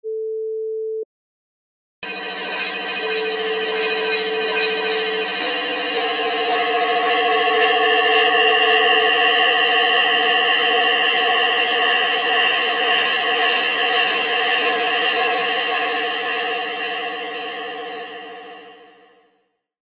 Created in outer space.